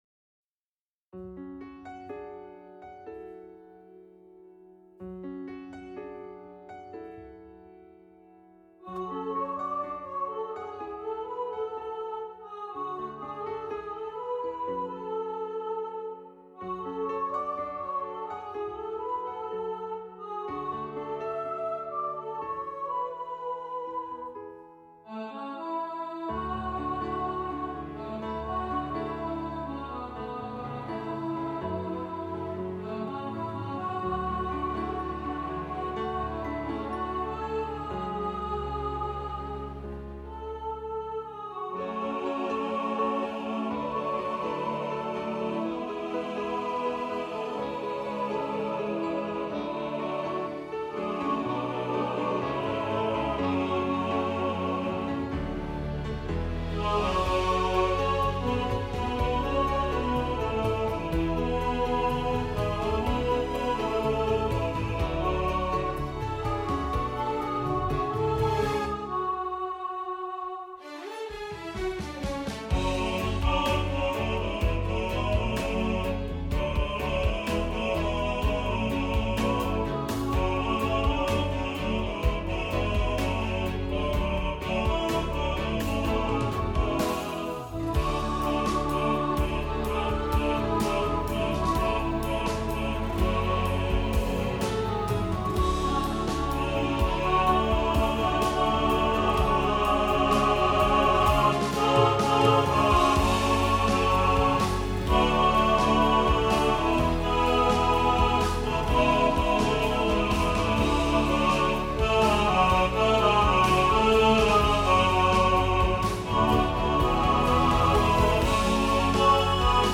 Speechless – All Voices | Ipswich Hospital Community Choir